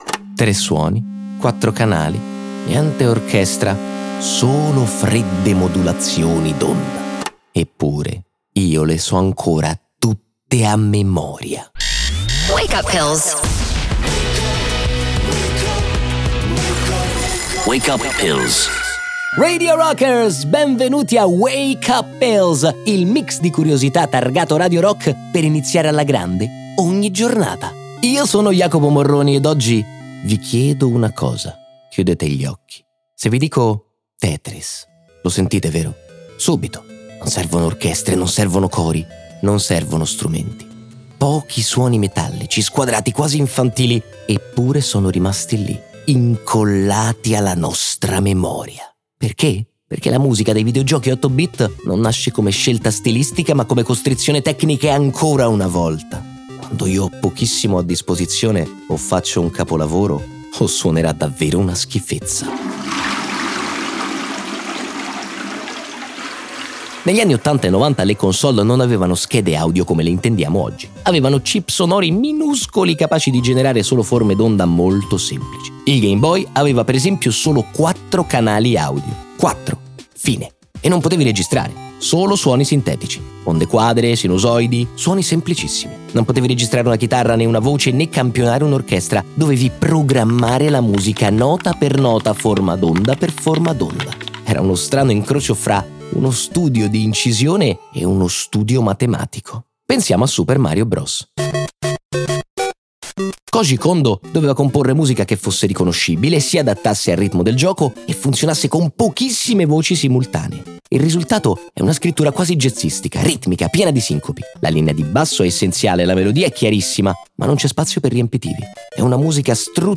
Wake Up Pills è un podcast Radio Rock Originals.